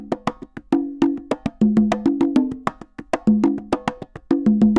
Congas_Salsa 100_7.wav